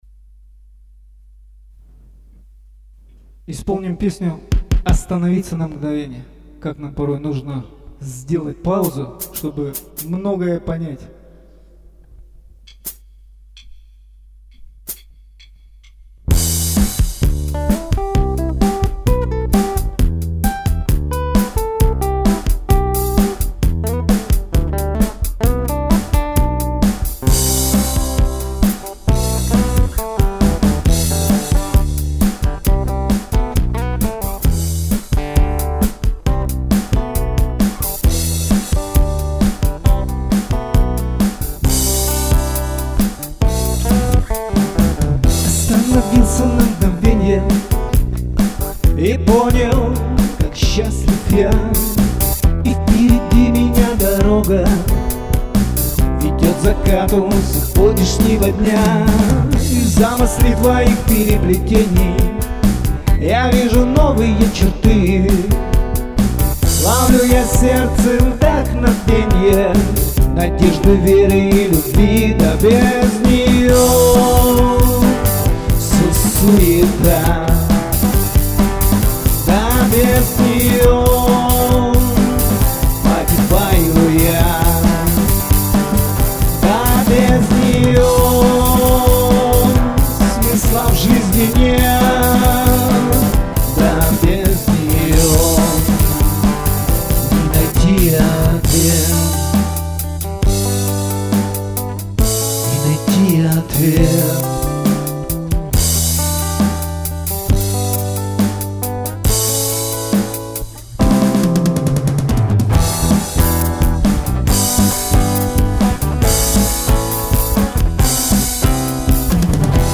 1. «Группа Д.В.С. – Остановиться на мгновенье.(авторская песня).» /